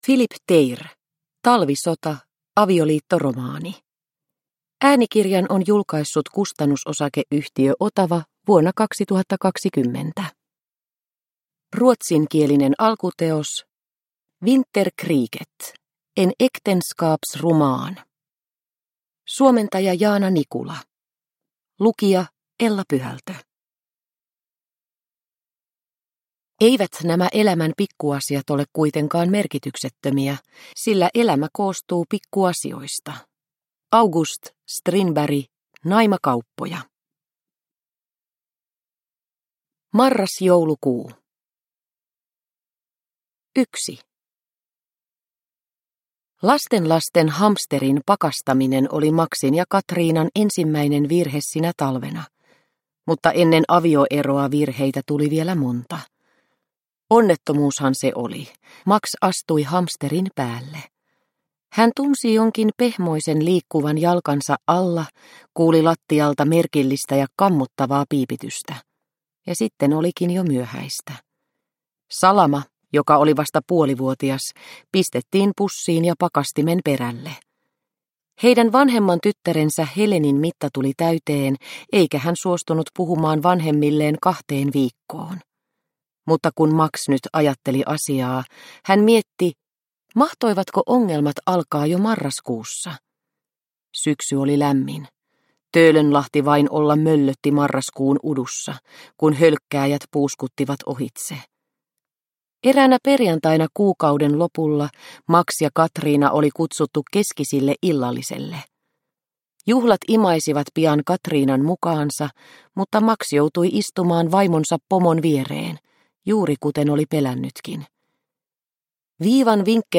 Talvisota. Avioliittoromaani – Ljudbok – Laddas ner